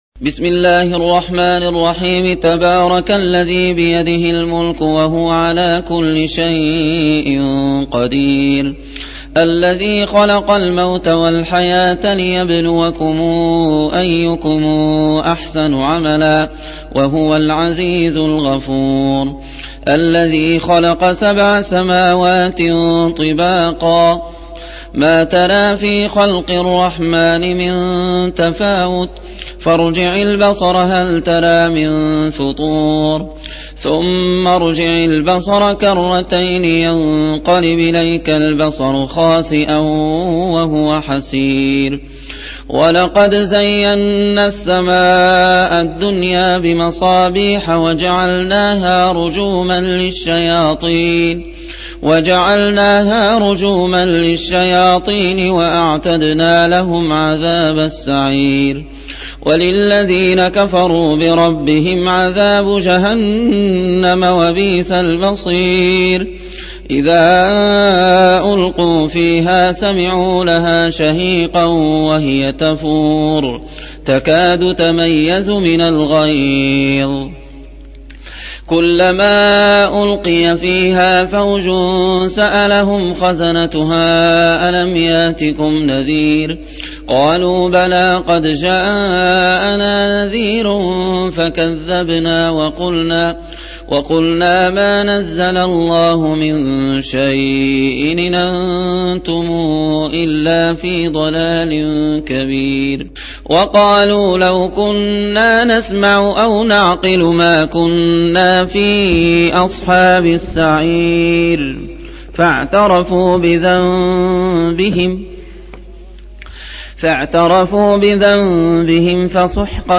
Surah Sequence تتابع السورة Download Surah حمّل السورة Reciting Murattalah Audio for 67. Surah Al-Mulk سورة الملك N.B *Surah Includes Al-Basmalah Reciters Sequents تتابع التلاوات Reciters Repeats تكرار التلاوات